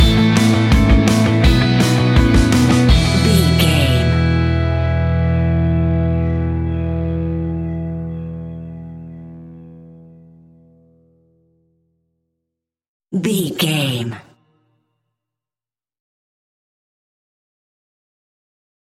Green Day Soundalike Stinger.
Ionian/Major
pop rock
energetic
uplifting
instrumentals
indie pop rock music
upbeat
groovy
guitars
bass
drums
piano
organ